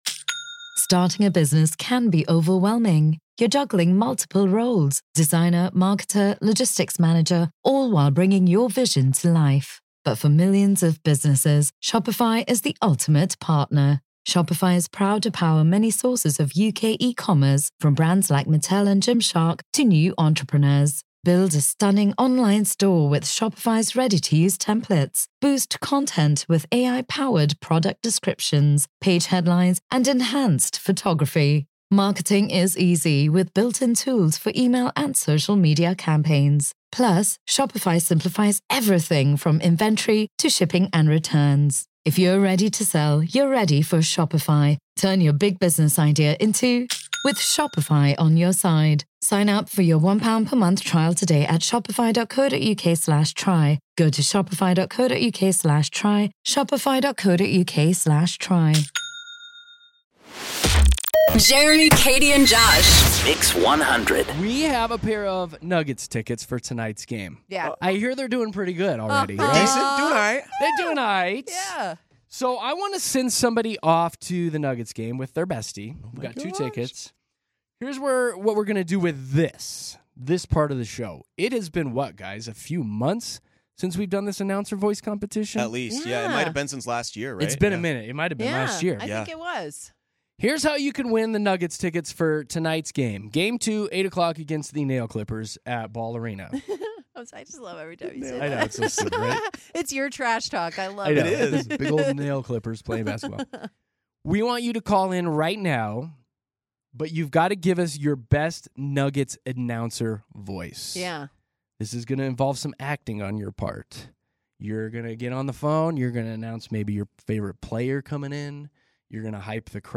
along with several others, called in to share their best Nuggets calls for a chance to win tickets to tonight's game.